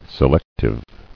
[se·lec·tive]